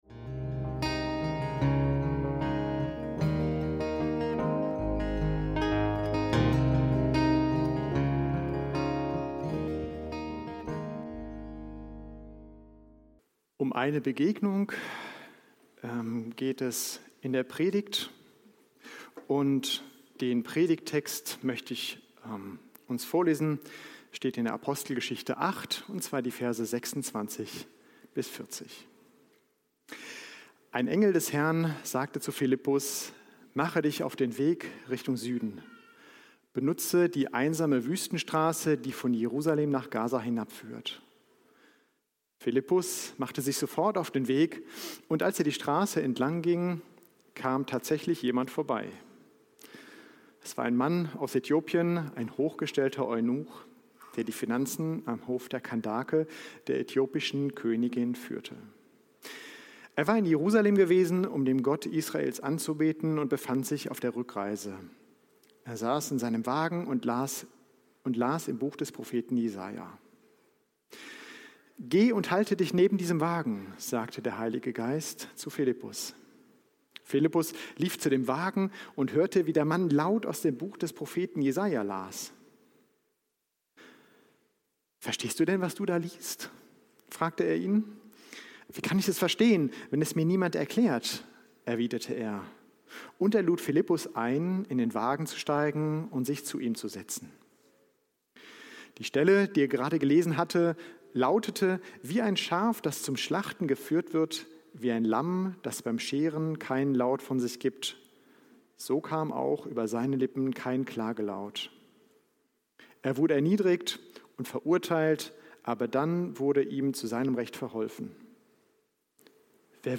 Begegnen und bezeugen - Gemeinde mit Mission - Predigt vom 08.02.2026